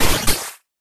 Cri de Qwilpik dans Pokémon HOME.